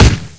mac10-1.wav